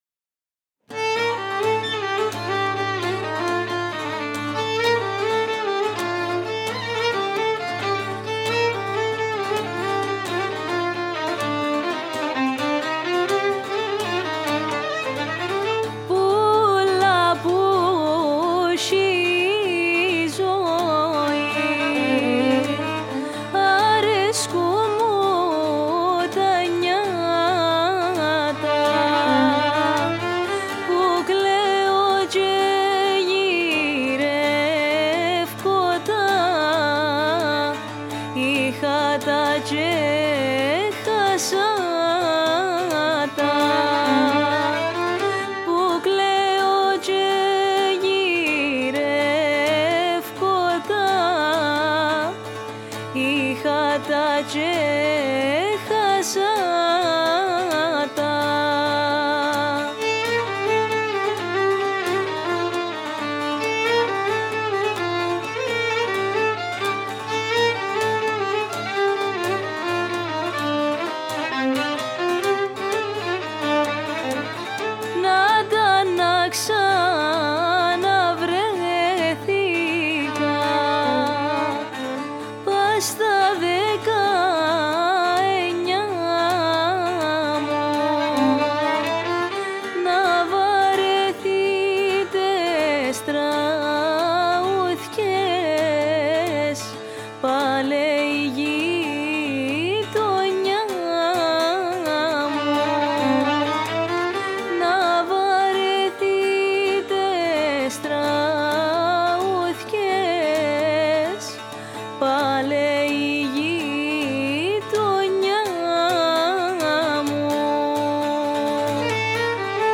Η κυπριακή παραδοσιακή μουσική